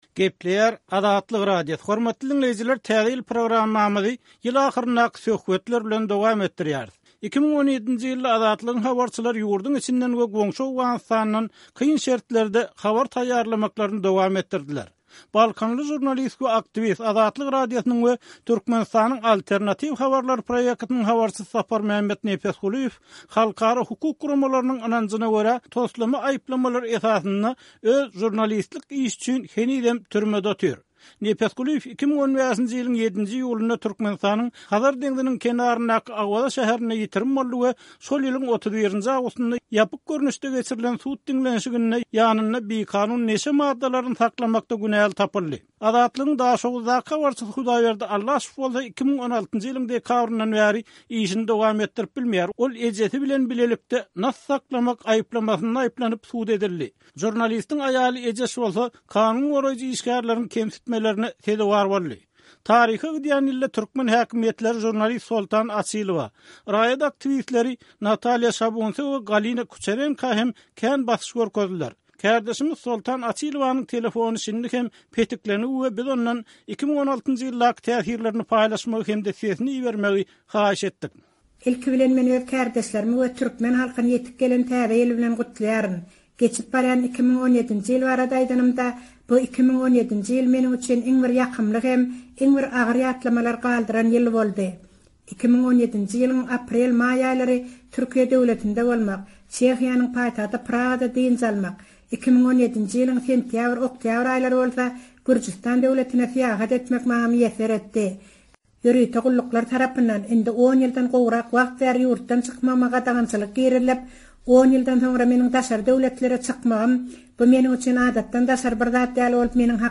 Azatlyk radiosy taryha gidýän 2017-nji ýylyň ahyrynda we 2018-nji ýylyň bosagasynda ýerli synçylar, habarçylar bilen gysgaça söhbetdeşlik gurady.